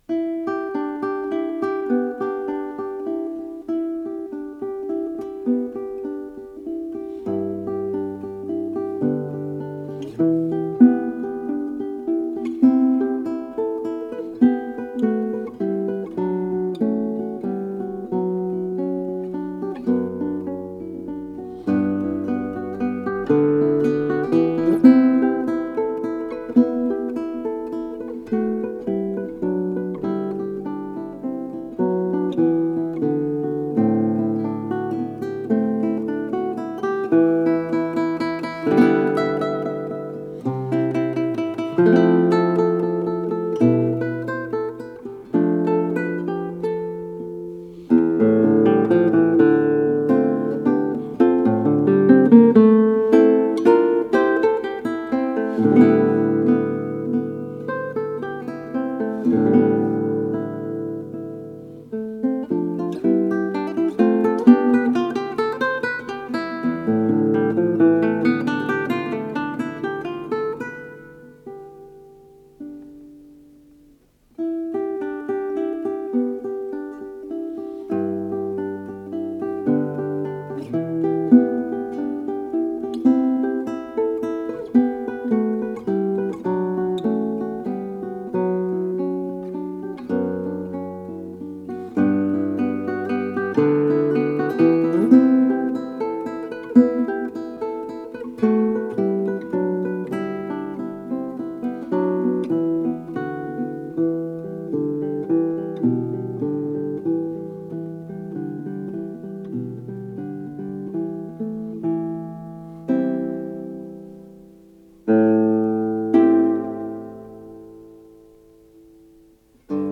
с профессиональной магнитной ленты
гитара шестиструнная